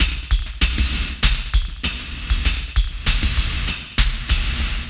98WAGONFX1-L.wav